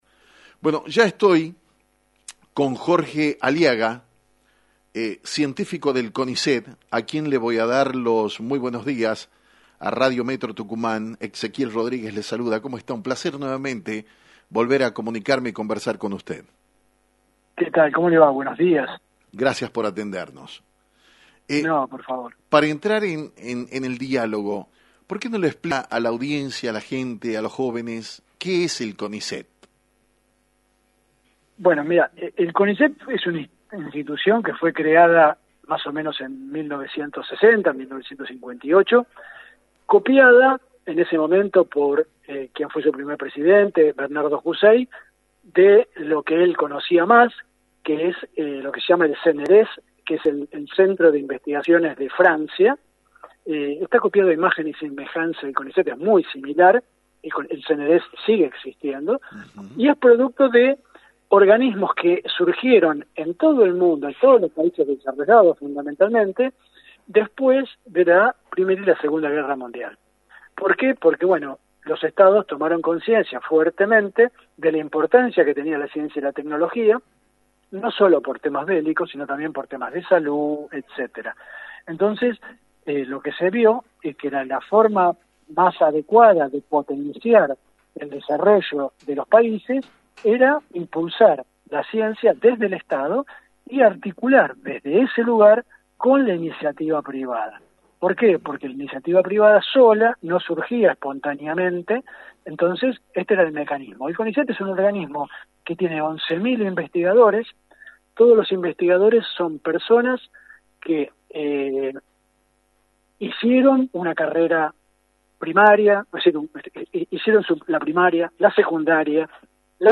En diálogo con Actualidad en Metro